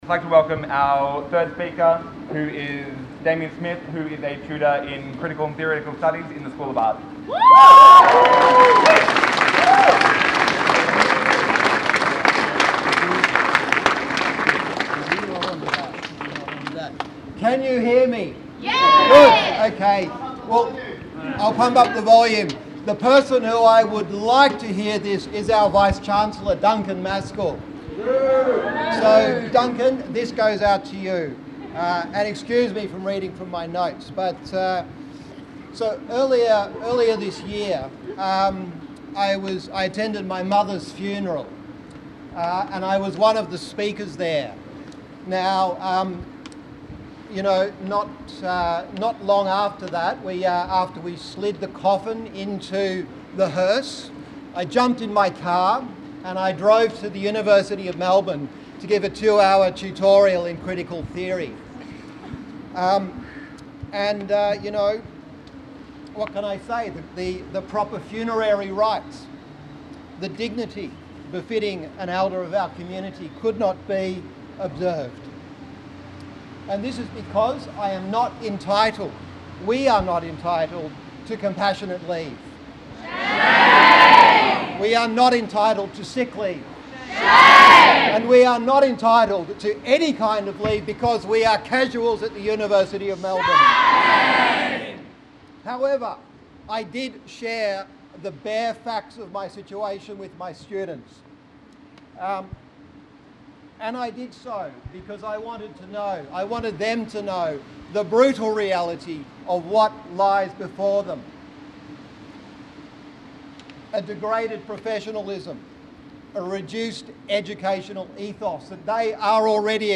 Lydia Thorpe Media Conference after Nazi Slur here II Senator Lidia Thorpe responses to on-line Nazi threats against her at a media conference outside the Exhibition Gardens on Thursday 5th Oct.